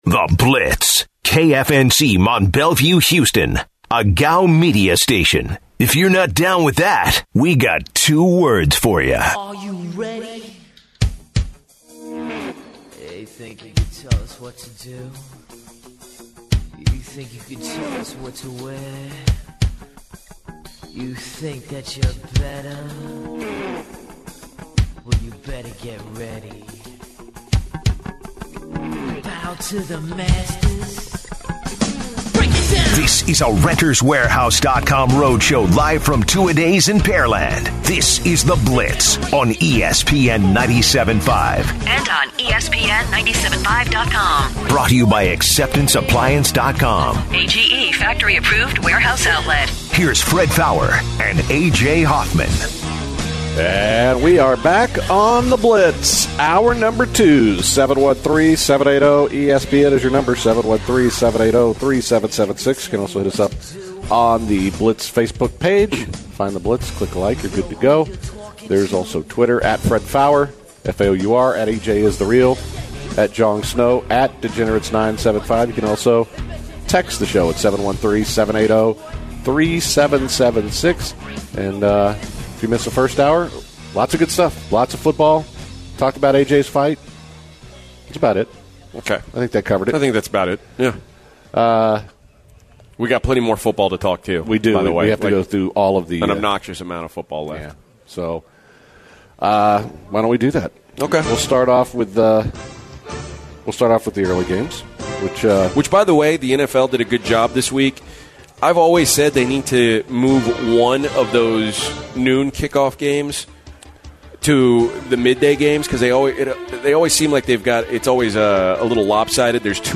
The guys began the hour with some talks of the NFL. They covered different teams, schedule balance and etc. Former NFL cornerback, Stanford Routt, joined the show to discuss some upcoming and past NFL games of the week. They then told the story of a guy who encountered and a survived the attack of a bar.